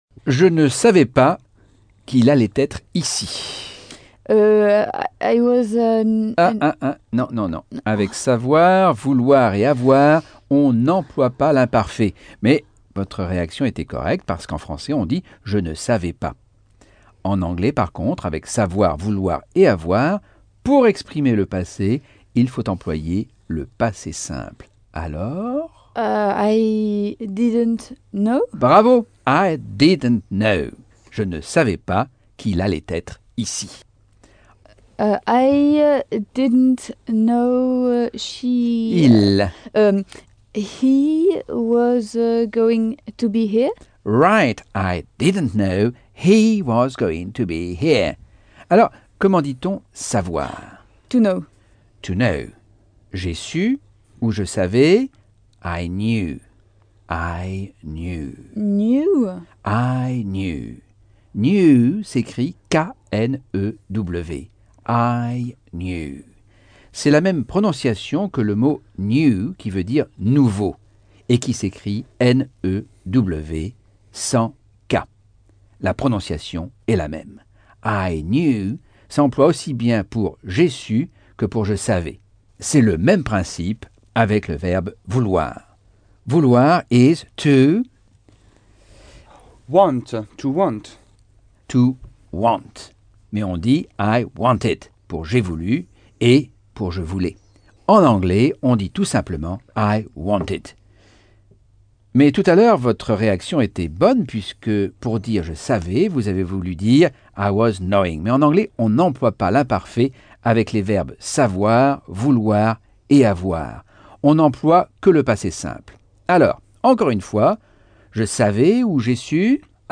Leçon 8 - Cours audio Anglais par Michel Thomas - Chapitre 7